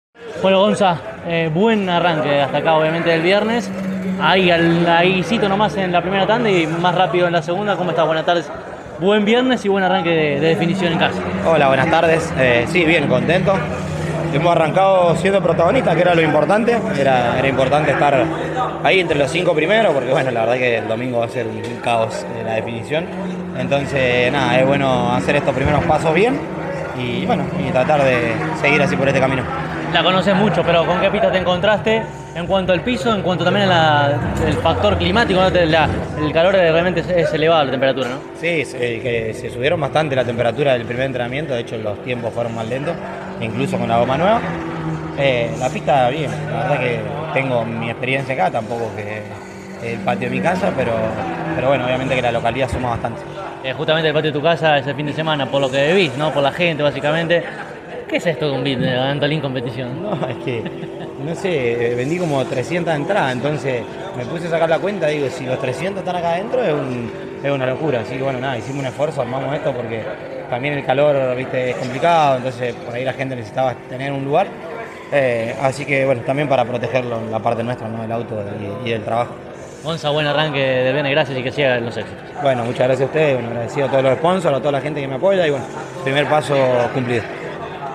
tras el ensayo, en diálogo con CÓRDOBA COMPETICIÓN